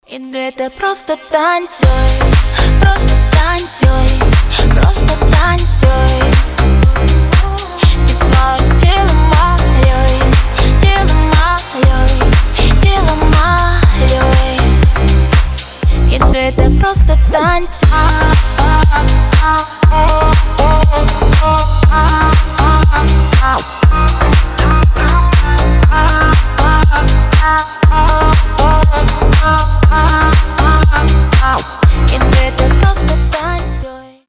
• Качество: 320, Stereo
громкие
зажигательные
EDM
future house
Зажигательный украинский клубнячок